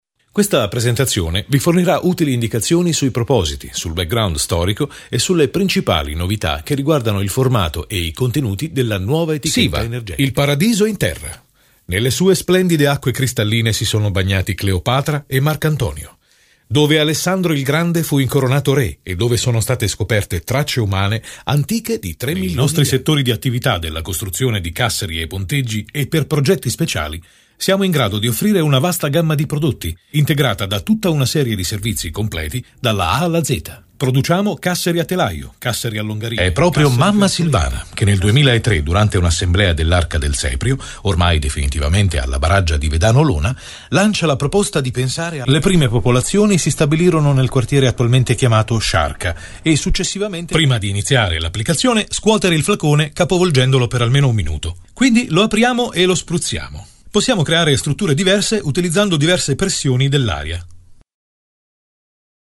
una voce maschile versatile: calda e profonda o dinamica e piena di energia.
Sprechprobe: Industrie (Muttersprache):
versatile Voice: warm and deep or dynamic and full of energy.